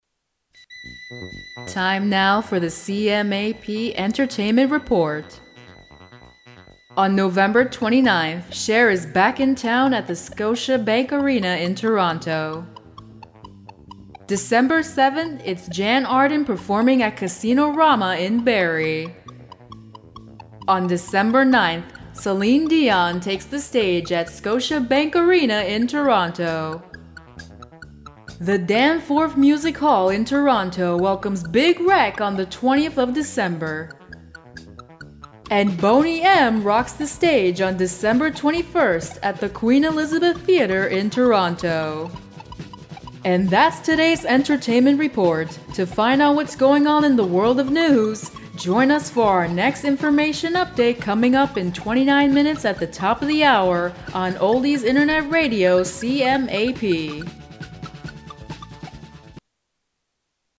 标签： 激情
配音风格： 浑厚 激情 磁性 大气